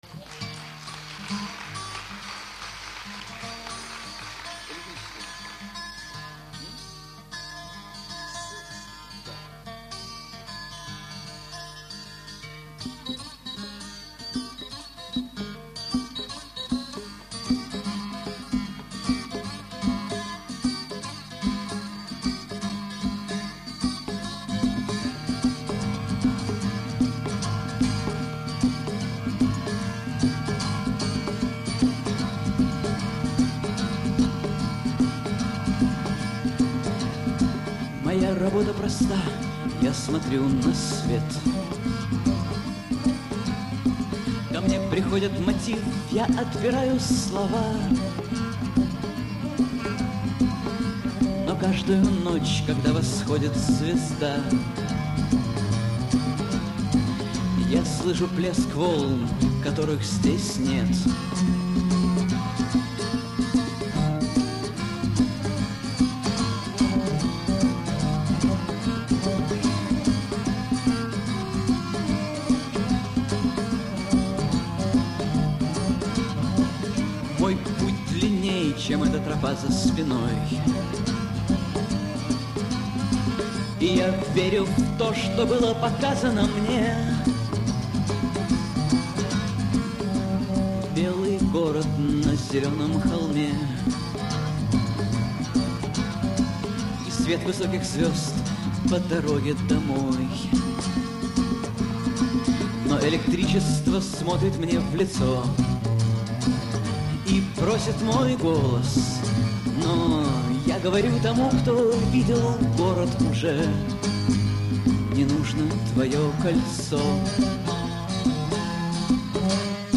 Акустический концерт